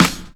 • Smooth Snare One Shot F Key 323.wav
Royality free snare drum tuned to the F note. Loudest frequency: 1969Hz
smooth-snare-one-shot-f-key-323-L67.wav